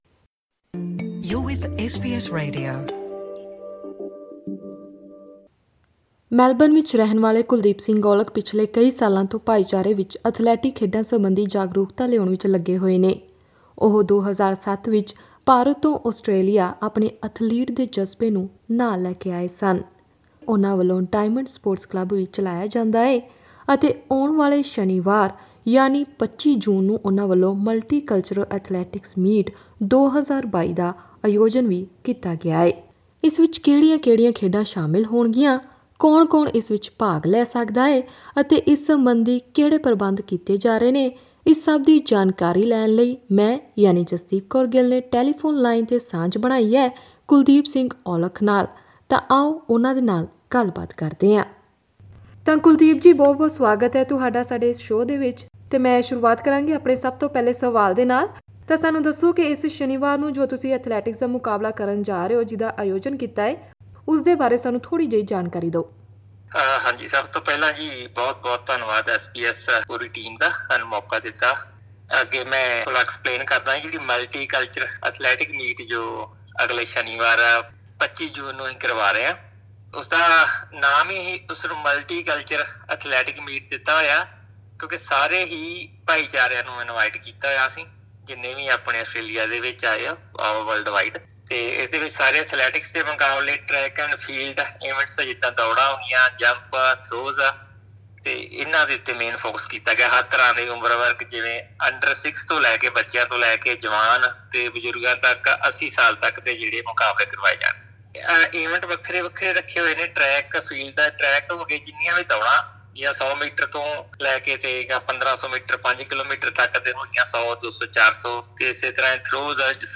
Click on the audio below to listen to the full interview in Punjabi: LISTEN TO ‘Ready for action’: Melbourne's Multicultural Athletics Meet 2022 SBS Punjabi 23/06/2022 09:49 Play Listen to SBS Punjabi Monday to Friday at 9 pm.